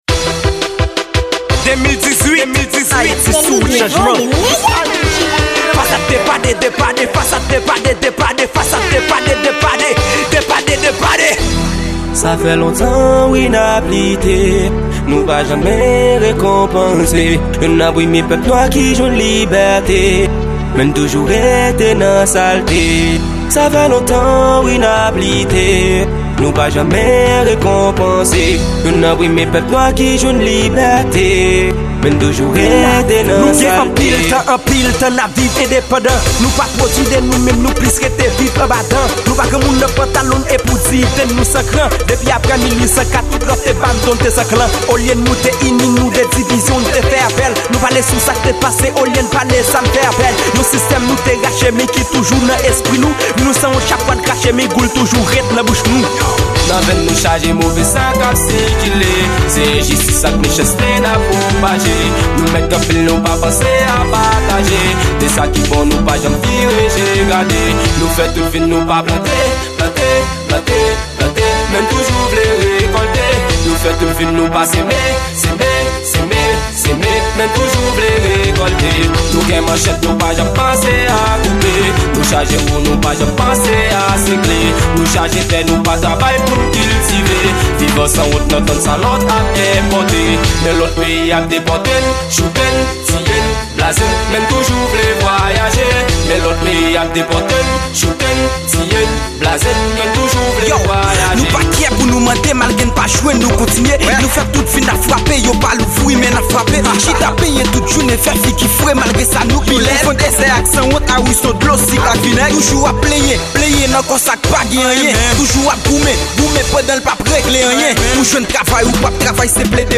Genre: Kanaval